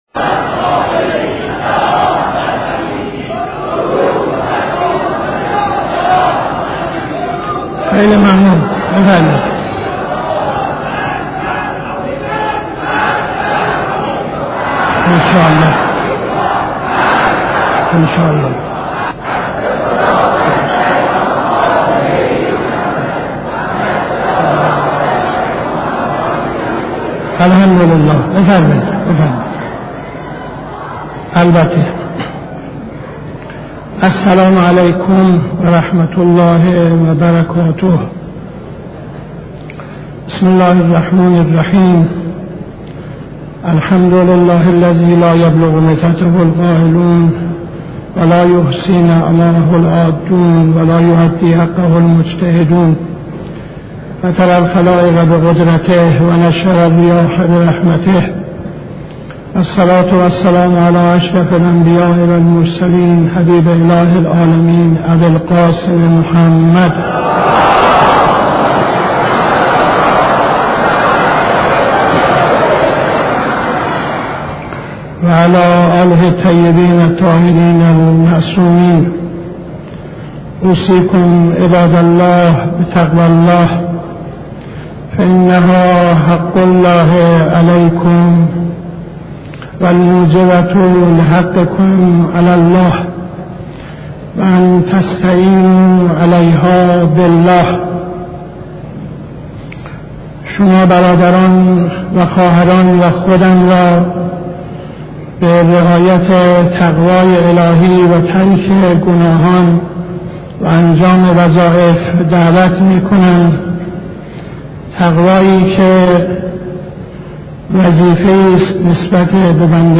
خطبه اول نماز جمعه 07-12-71